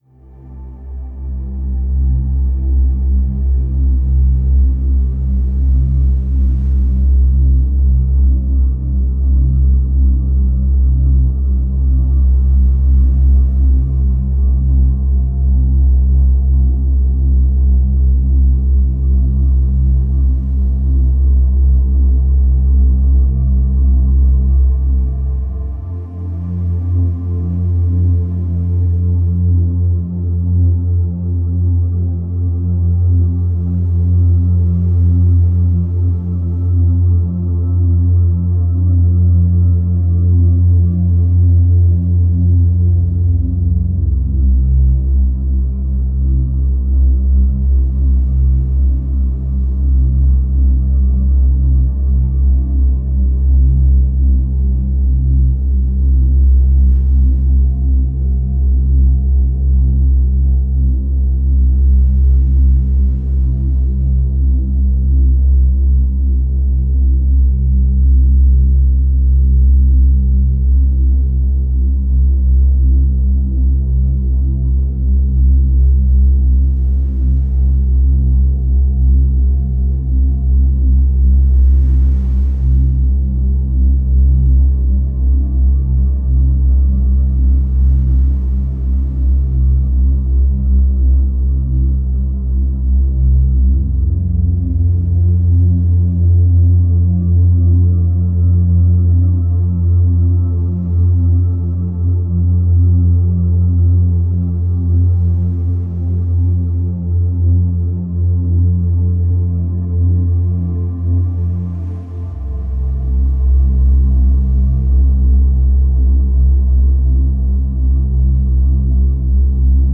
Une immersion vibratoire pour éveiller votre être intérieur